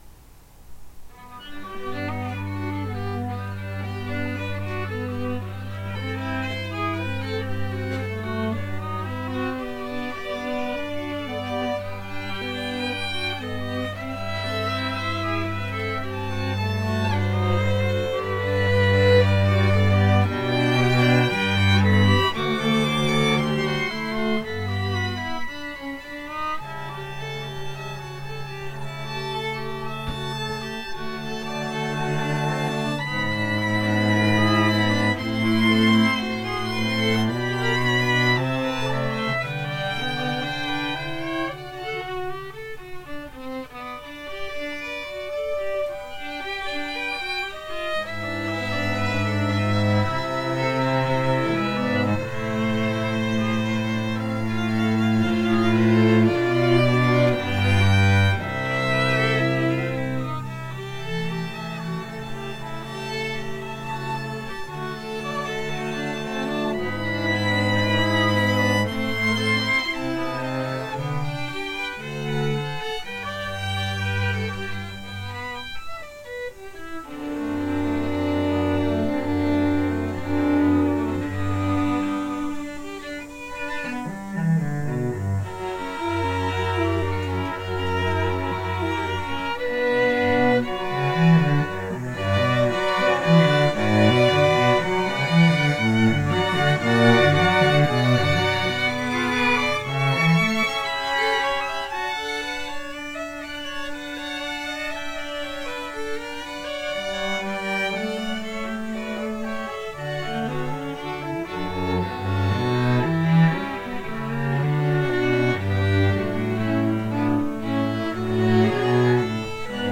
Chamber Groups
Andante